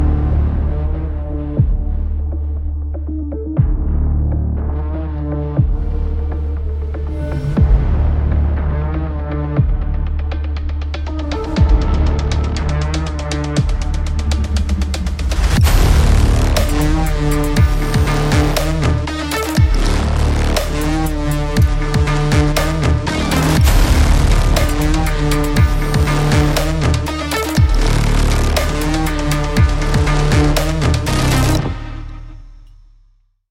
从激烈的金属 Riff 到电影般的神秘音效，所有这一切都集成在一个通用的管理生态系统中。